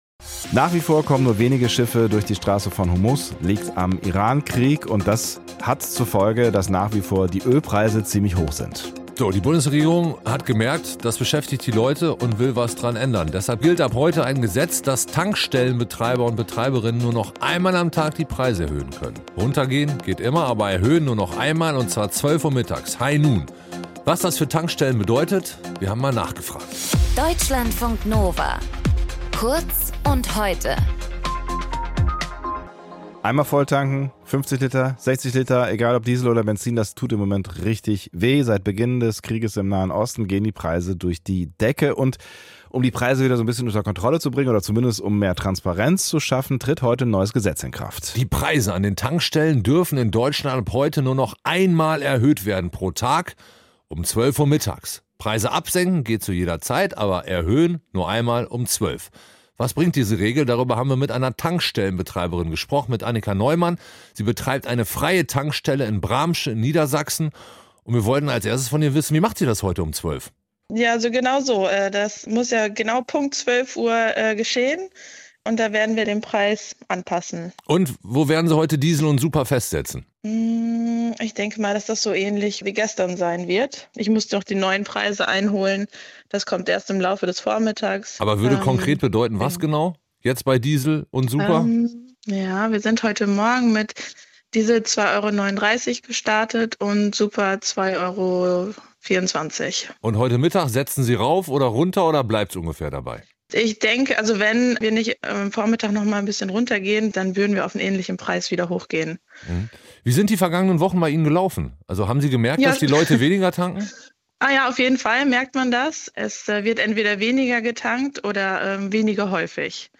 Moderation
Gesprächspartnerin